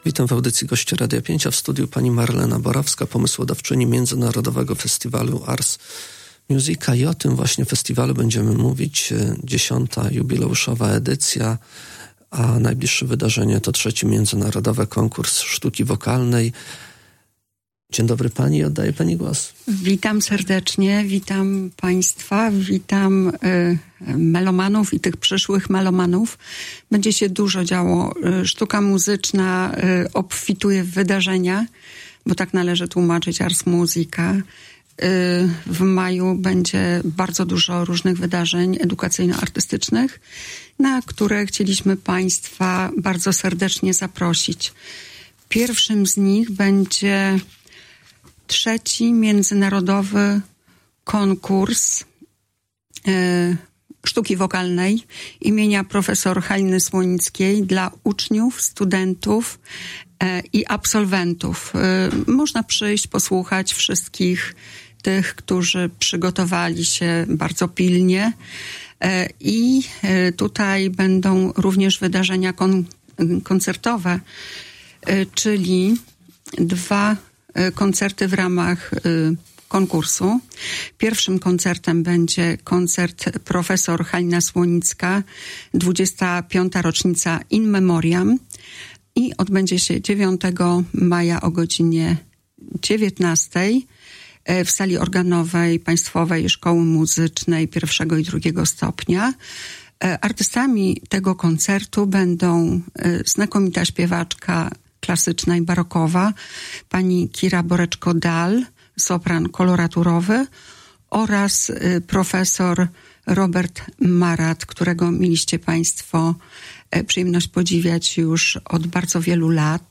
O szczegółach mówiła w Radiu 5